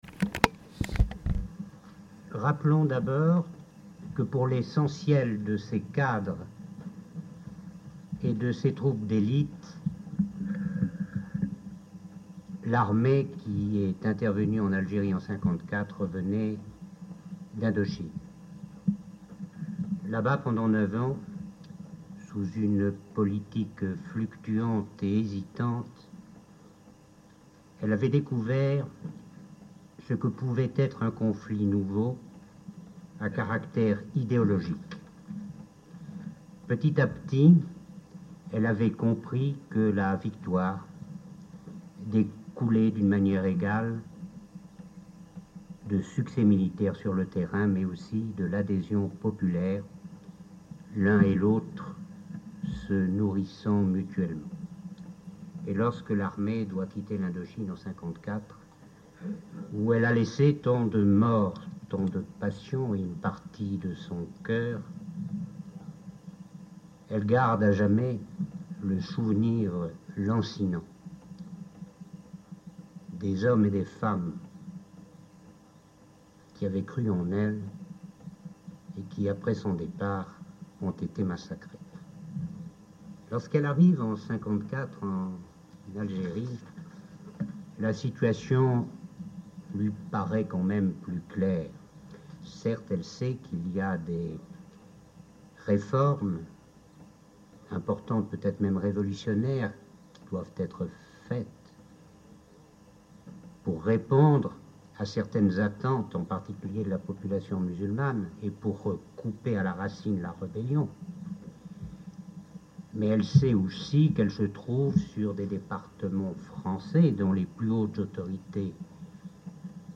Témoignage d'Hélie Denoix de Saint Marc sur le drame vécu par l'armée en Algérie lors de la veillée-souvenir du 11 mars 1983.